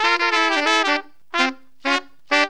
HORN RIFF 25.wav